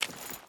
Water Chain Walk 4.wav